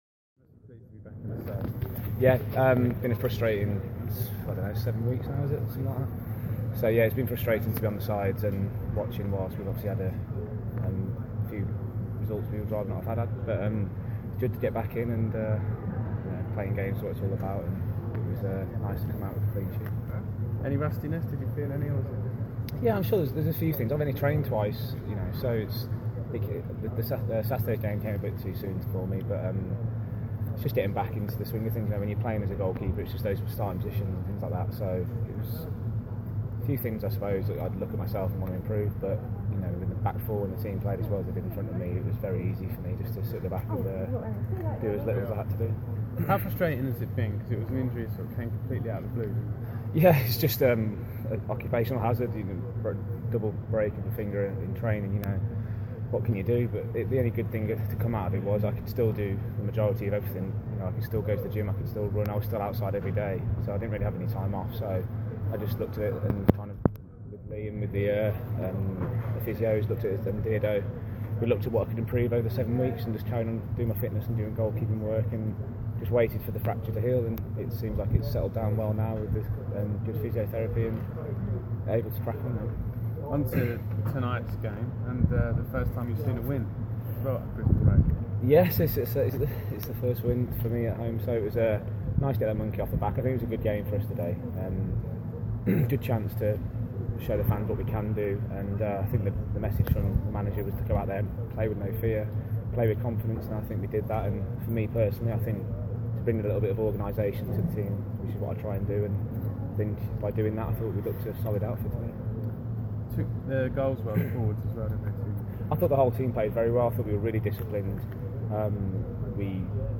speaking after Orient's home victory against Northampton in the JPT.